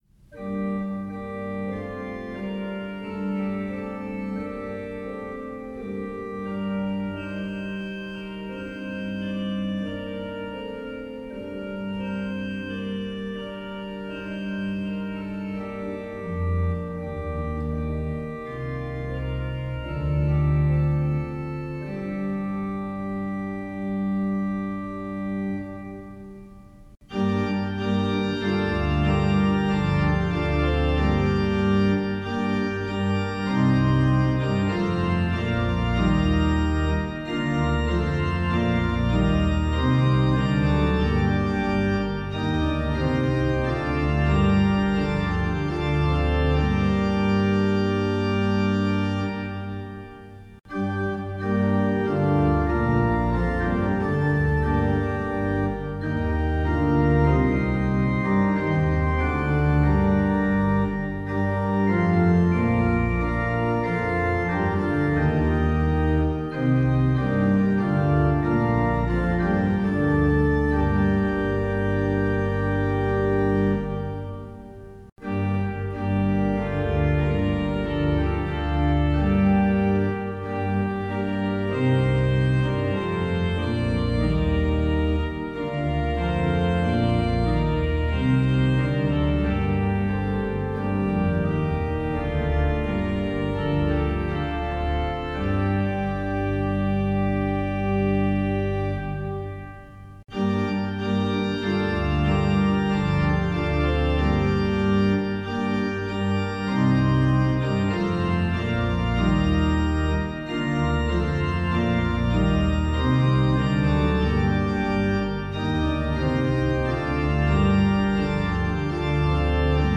Hymn for the Week